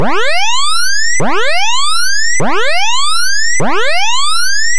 Emergency Alert Alarm